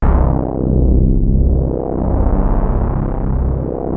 JUP.8 C2   2.wav